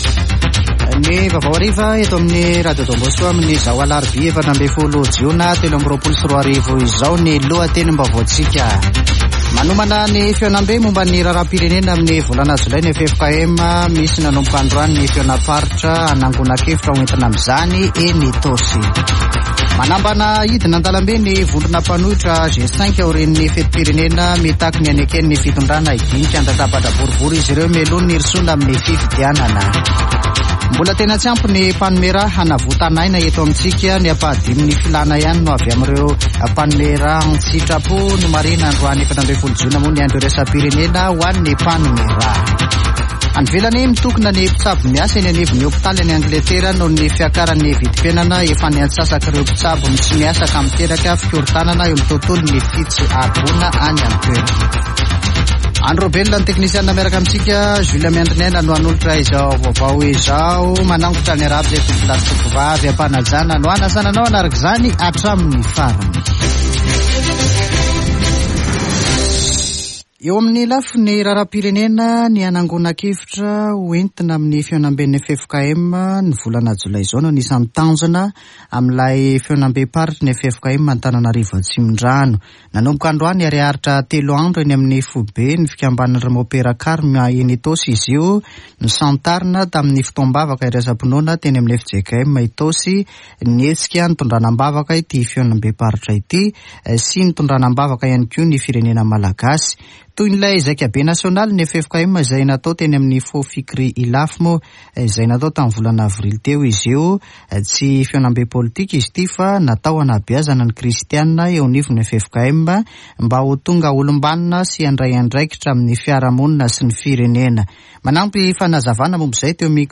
[Vaovao hariva] Alarobia 14 jona 2023